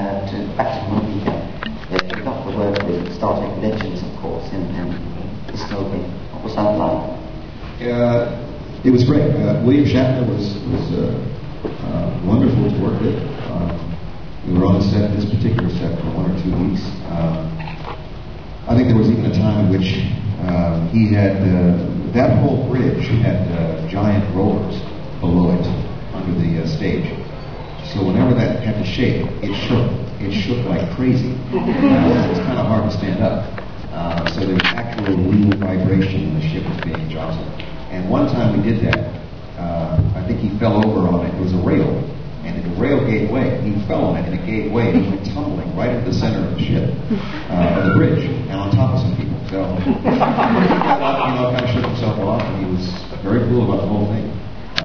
Now, then, came the high-point (for us Trekkers) of the day: the interview with Tim Russ!